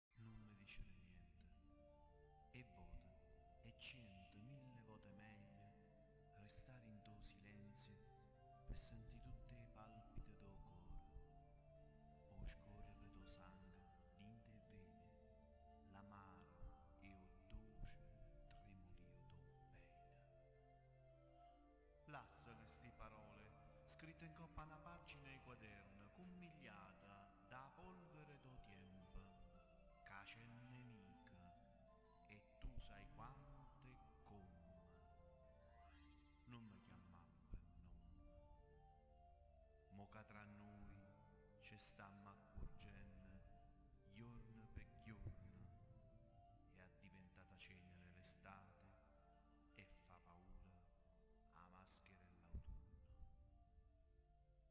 La poesia sonora si collega per un verso alla musica e per un altro al teatro, da un lato abbina il testo poetico ad un brano musicale, dall'altro sfrutta la sonorità del linguaggio.